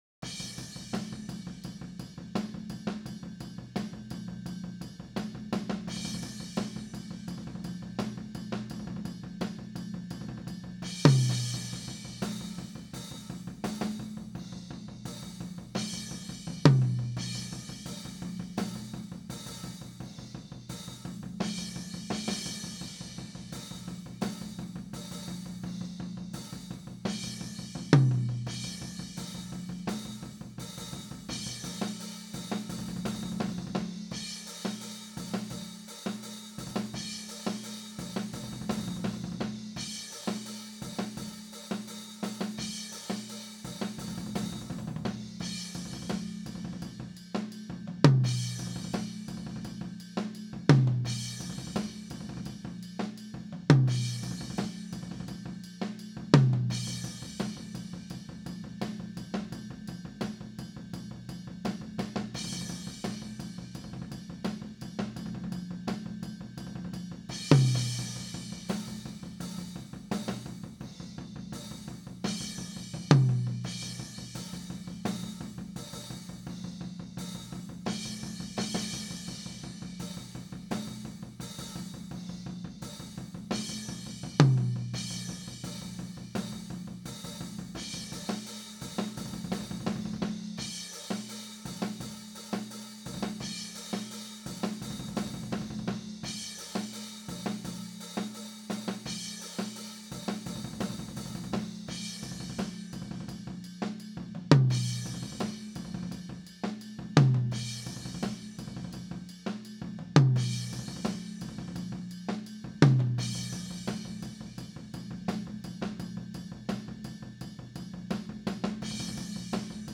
humanized-Tom1-10.wav